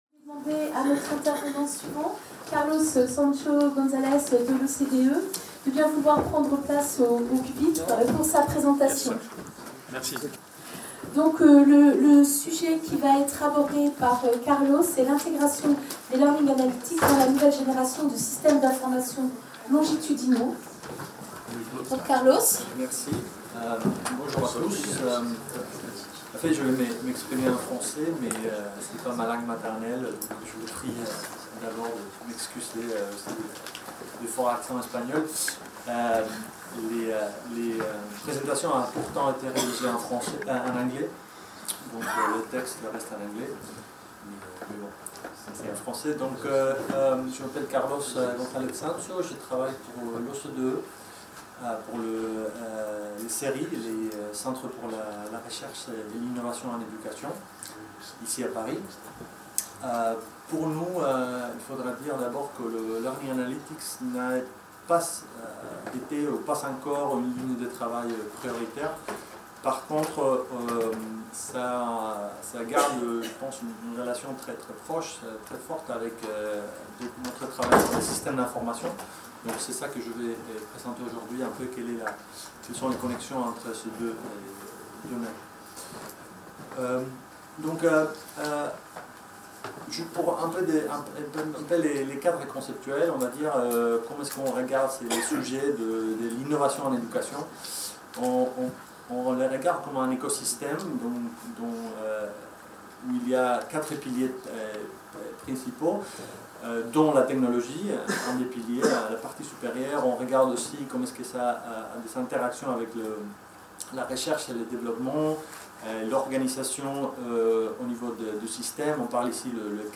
Séminaire du 8 décembre 2015 dédié aux "Learning Analytics", organisé avec le soutien du MENESR. Des experts nationaux et internationaux donnent une synthèse du sujet, afin d'ouvrir une discussion avec les participants sur les actions à entreprendre, les domaines à investiguer...